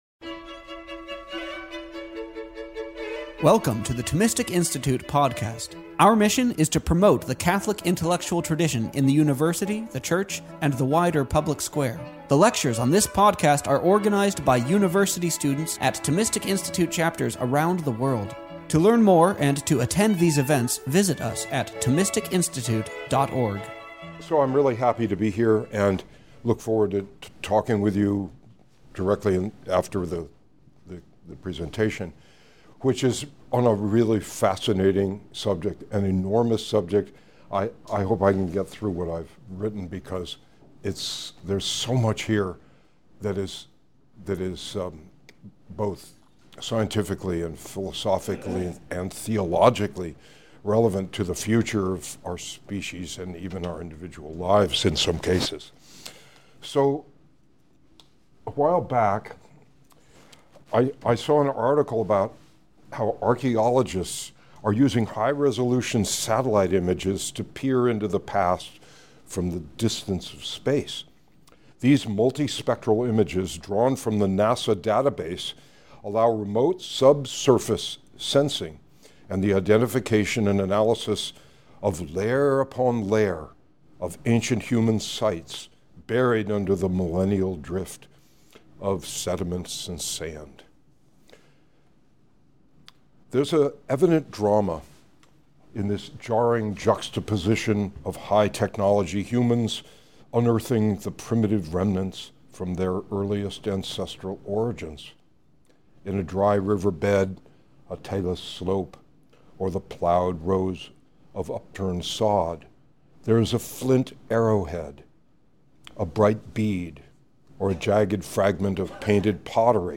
This lecture was offered at NYU on November 10th, 2018.